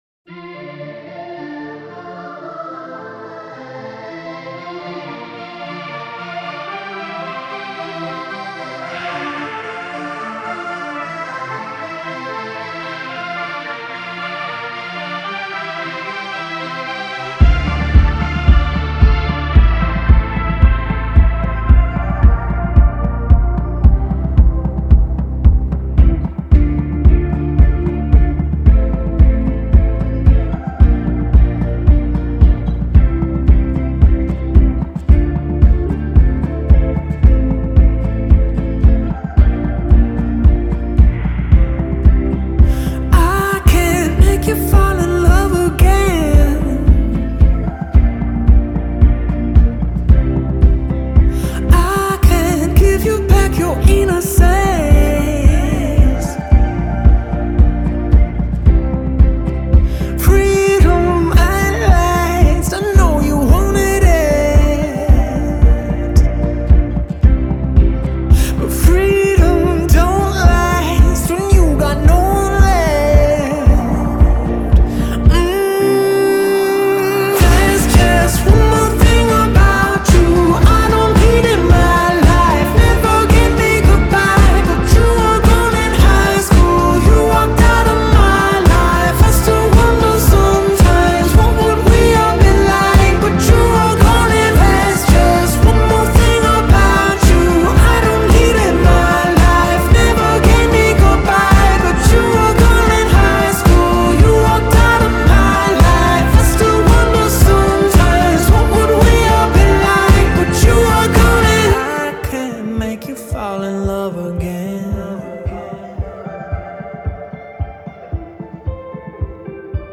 • Жанр: Alternative